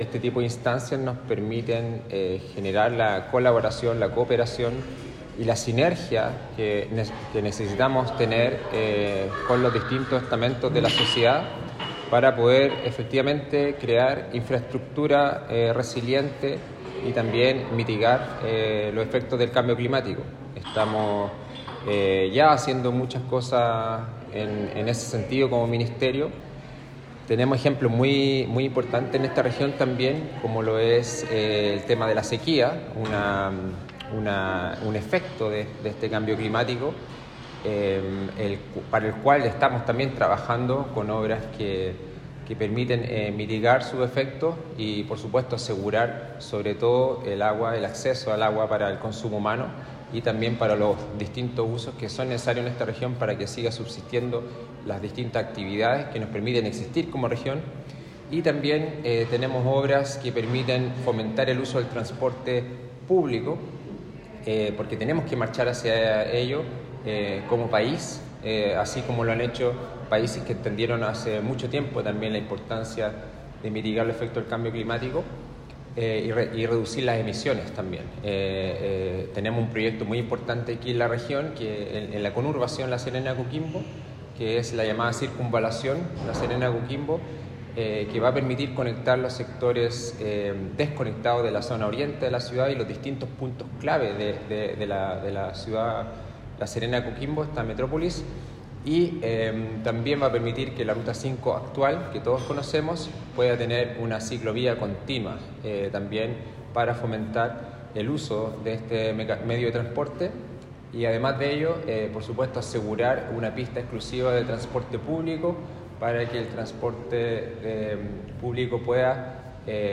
Para el SEREMI de Obras Públicas de la región, Javier Sandoval Guzmán, esta instancia permite
SEREMI-MOP.mp3